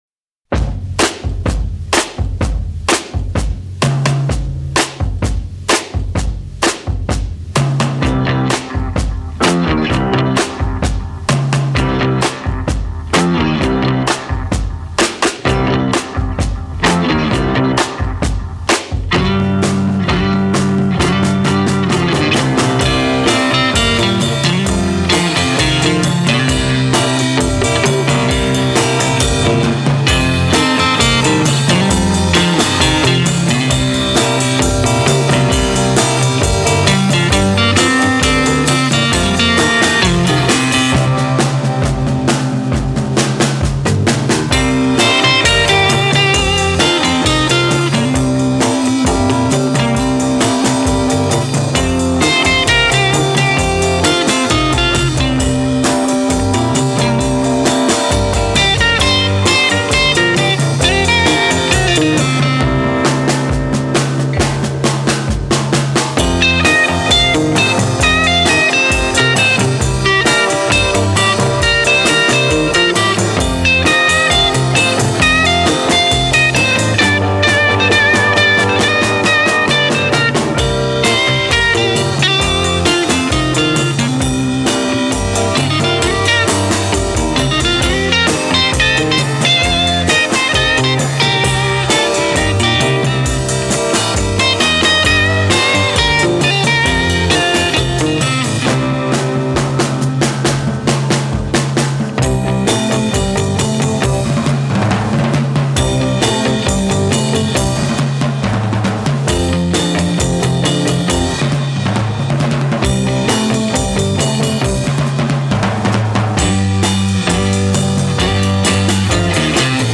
Original Mono